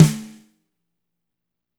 -FAT SNR1F-L.wav